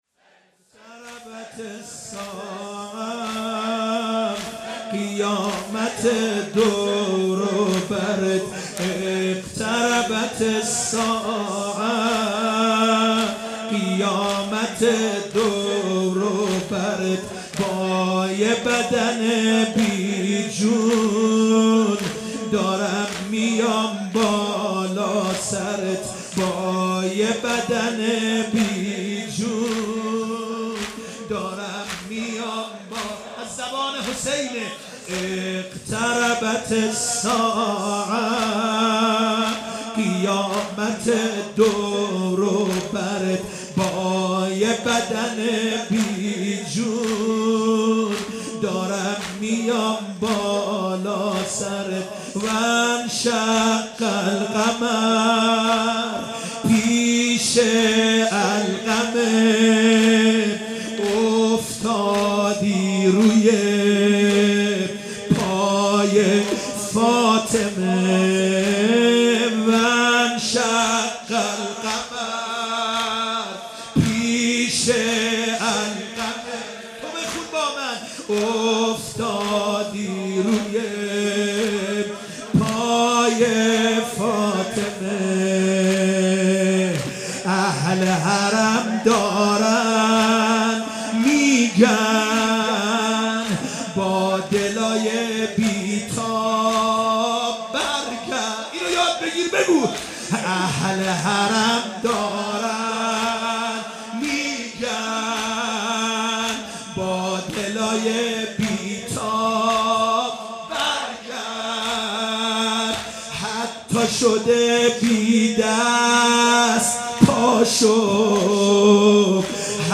شب تاسوعا محرم 96 - زمینه - با یه بدن بی جون دارم